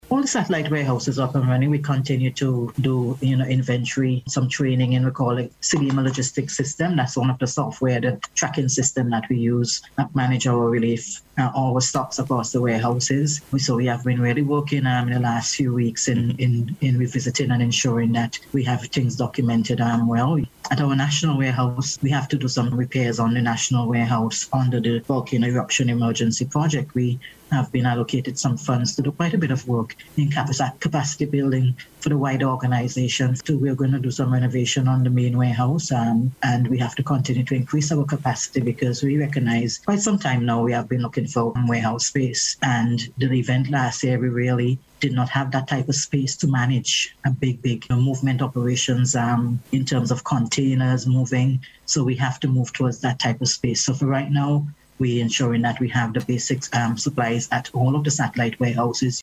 That is according to Director of NEMO, Michelle Forbes while speaking on issues relating to the hurricane season on NBC Radio yesterday.